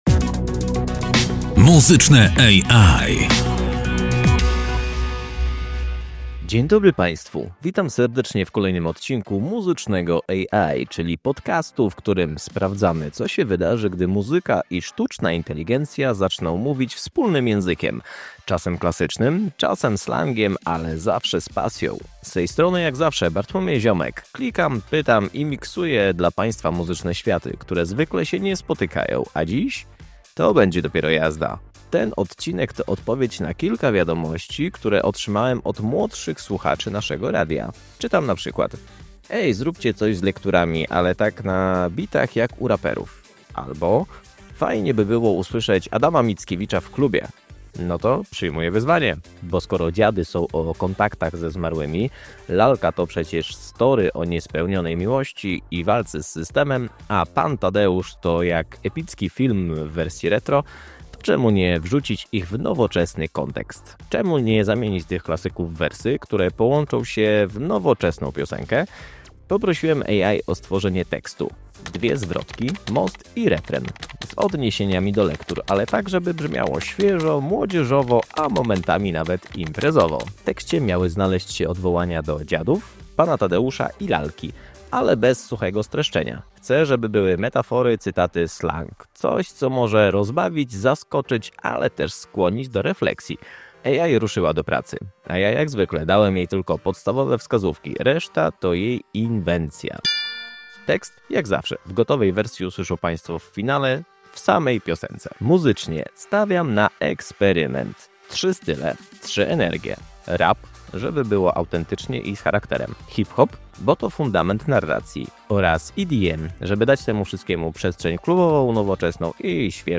W tym odcinku „Muzycznego EjAj” klasyka szkolnych lektur dostaje nowe życie – na bitach, w rytmie i z młodzieżowym flow.
Muzycznie postawiliśmy na mieszankę trzech stylów: rap – dla wyrazu i charakteru, hip-hop – dla narracyjnego sznytu, i EDM – żeby wszystko miało nowoczesny, taneczny vibe. Spośród czterech wersji wygenerowanych przez AI, wybraliśmy tę jedną, w której refren wchodzi idealnie, a beat niesie tekst z lekkością i energią.